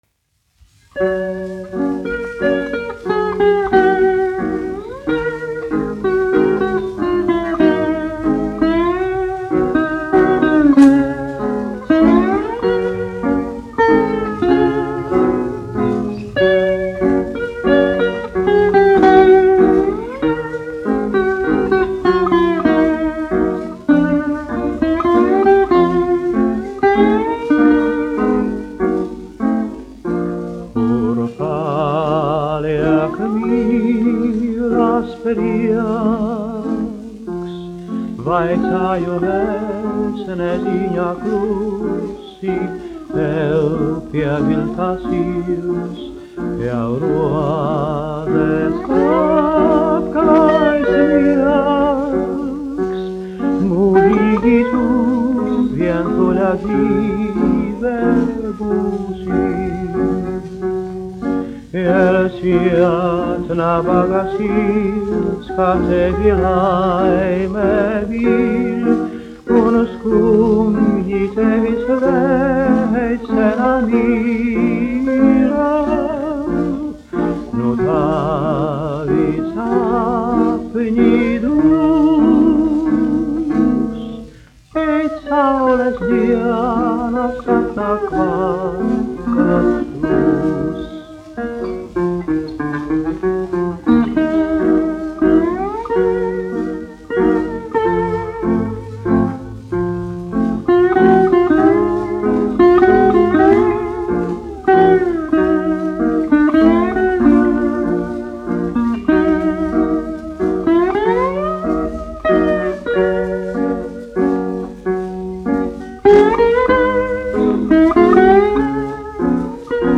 dziedātājs
1 skpl. : analogs, 78 apgr/min, mono ; 25 cm
Populārā mūzika
Latvijas vēsturiskie šellaka skaņuplašu ieraksti (Kolekcija)